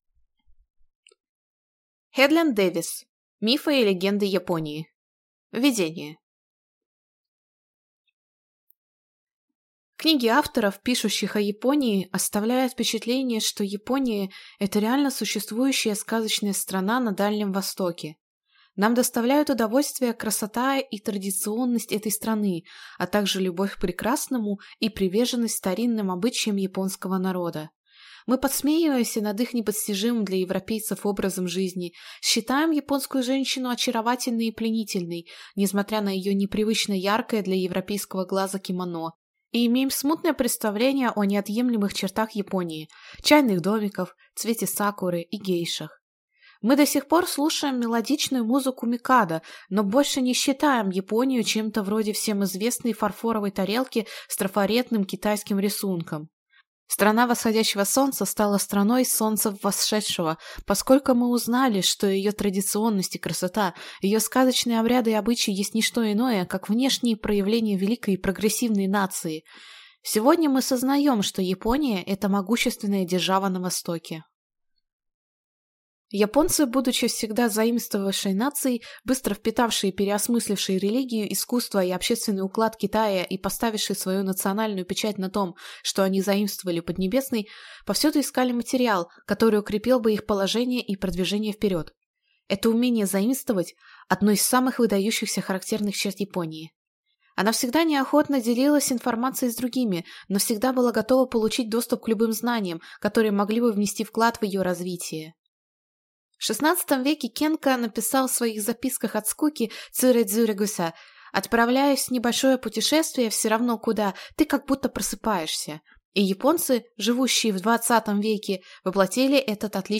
Аудиокнига Мифы и легенды Японии | Библиотека аудиокниг